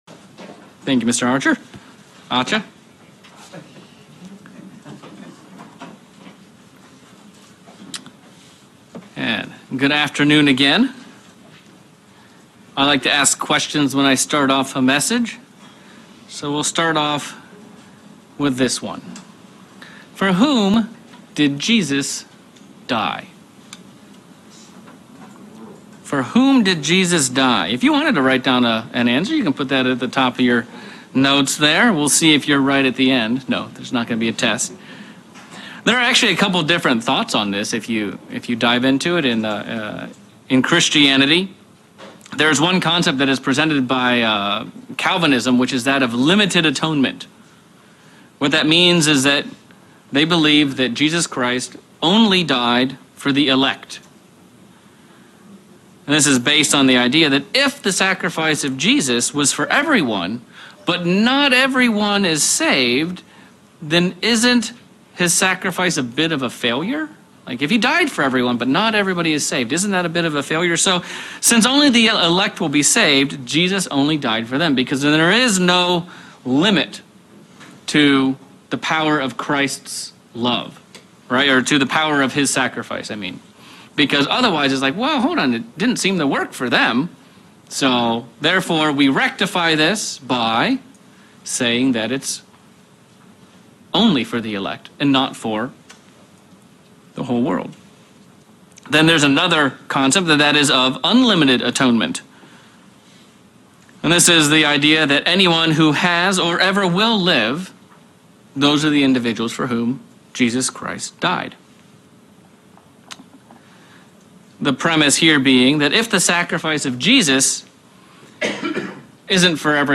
Sermon looking at the question of who Christ died for? 1.